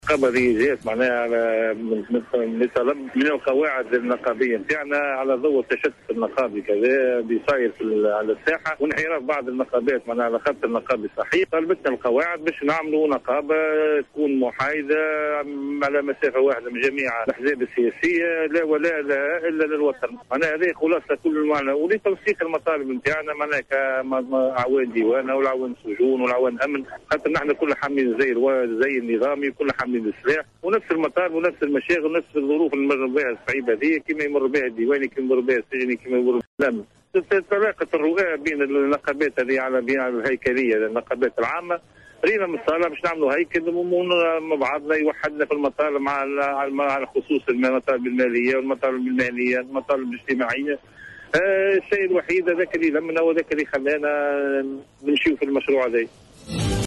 تم اليوم الأربعاء 27 نوفمبر 2013 خلال مؤتمر صحفي الإعلان عن نقابة قوات الأمن...